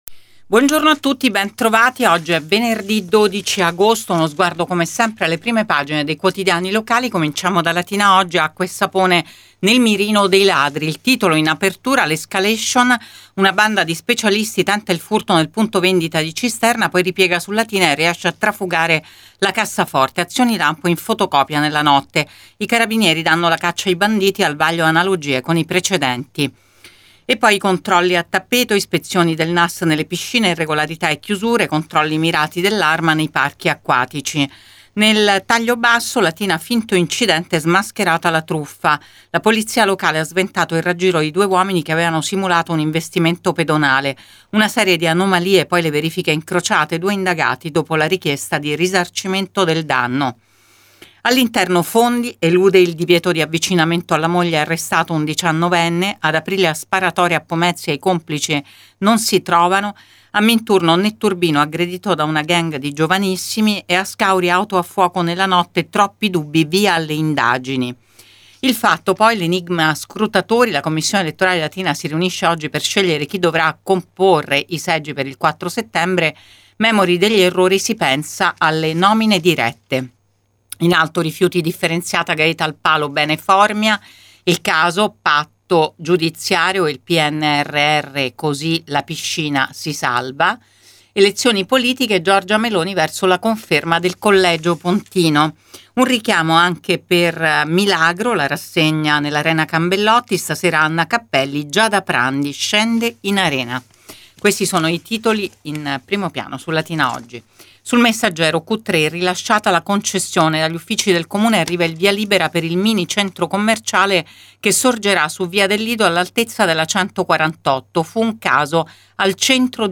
LATINA – Qui trovate Prima Pagina, ora solo in versione web, per dare uno sguardo ai titoli di Latina Editoriale Oggi e Il Messaggero Latina. (audio dopo la pubblicità)